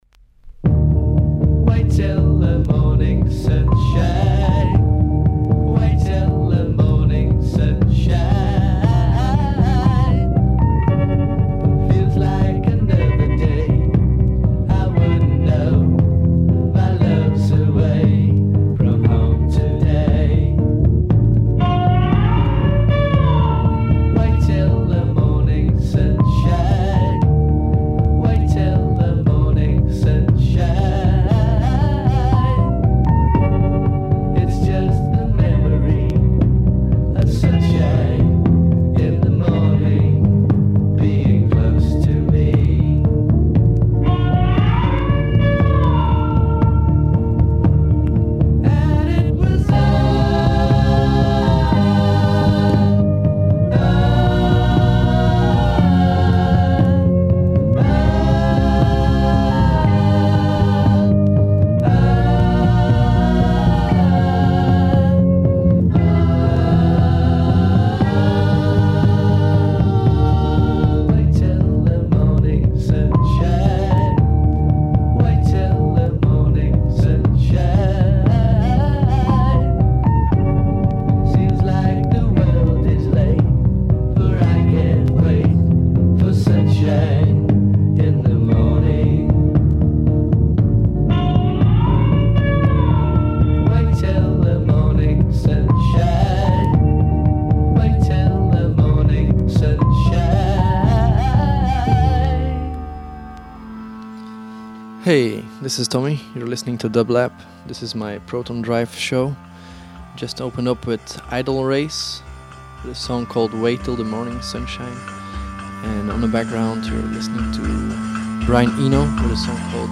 Avant-Garde Electronic Psych